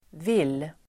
Uttal: [vil:]